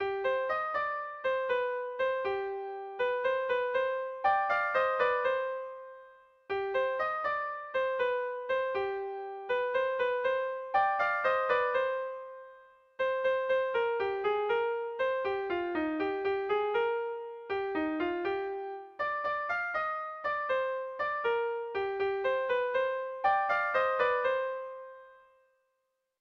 Irrizkoa
Antzinako doinua Ch.Bordesen iritzian.
7A / 8A / 8 / 8A / 10 / 8A (hg) | 7A / 8A / 16A / 18A (ip)
ABDE